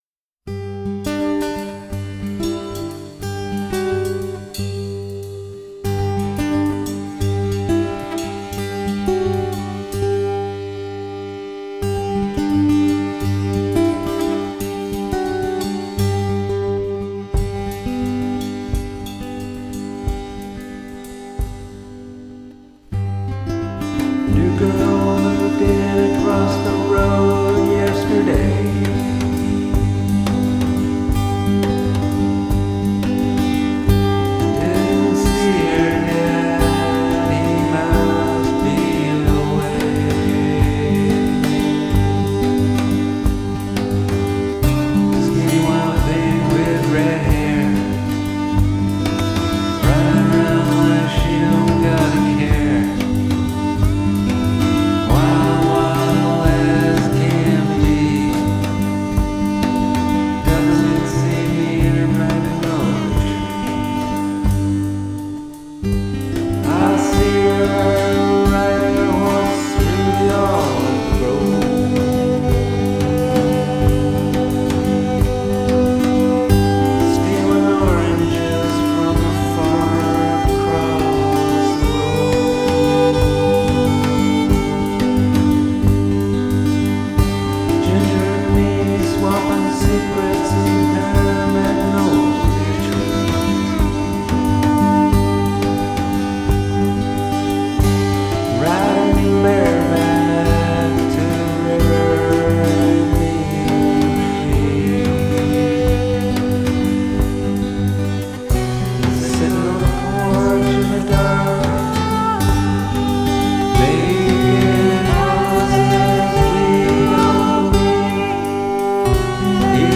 Recorded: FOSS Studio, Edmonton, Alberta
viola; violin